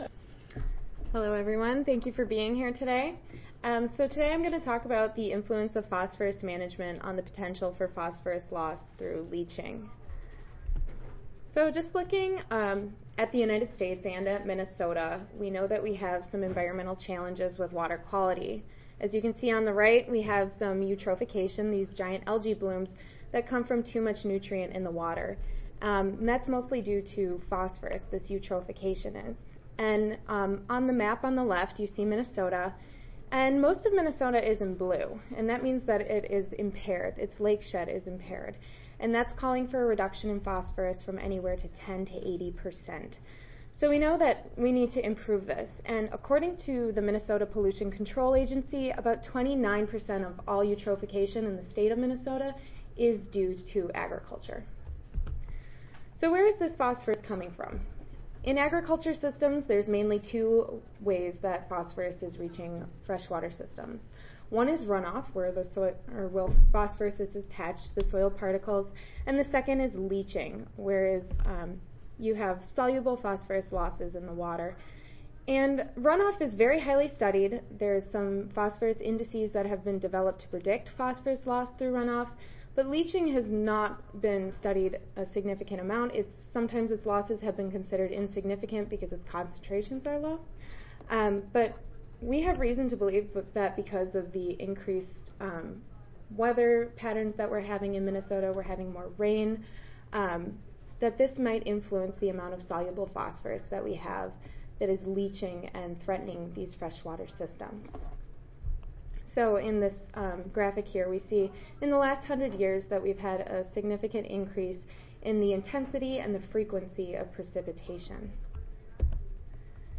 See more from this Division: SSSA Division: Soil Fertility and Plant Nutrition See more from this Session: M.S. Oral Competition